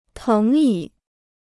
藤椅 (téng yǐ) Kostenloses Chinesisch-Wörterbuch